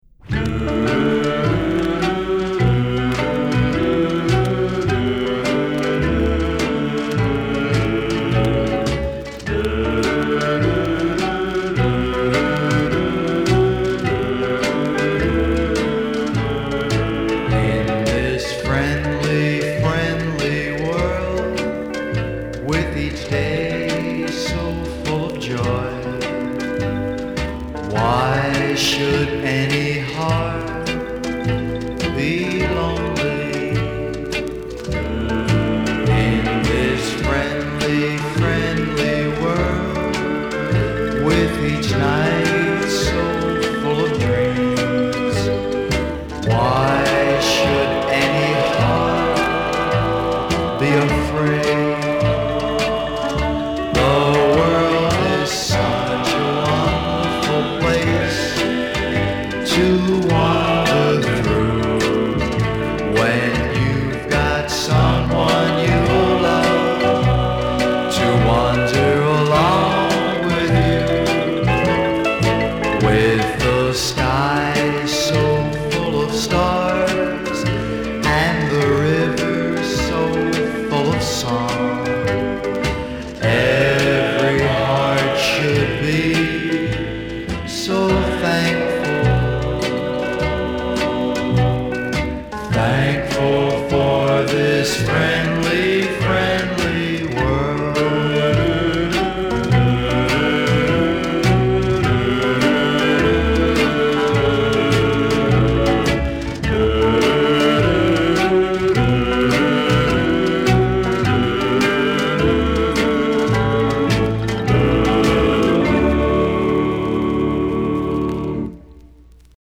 ラフなガレージ感覚のあるロッカー。